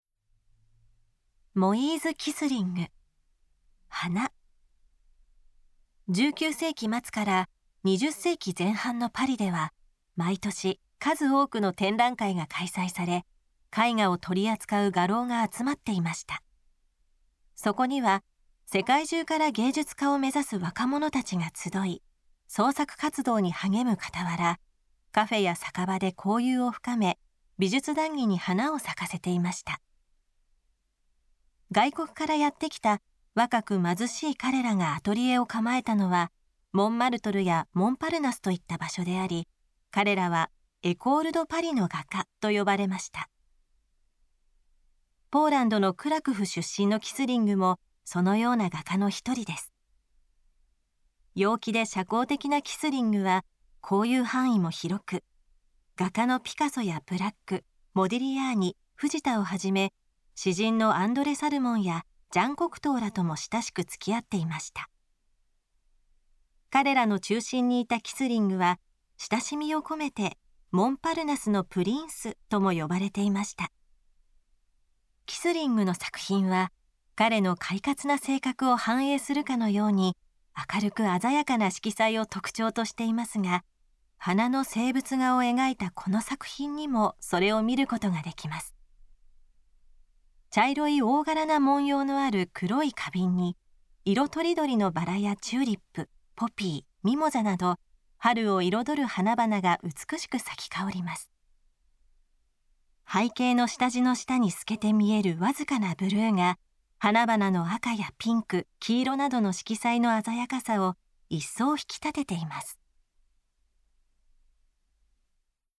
作品詳細の音声ガイドは、すべて東京富士美術館の公式ナビゲーターである、本名陽子さんに勤めていただいております。本名さんは声優、女優、歌手として幅広く活躍されています。